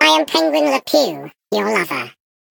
Sfx_tool_spypenguin_vo_love_01.ogg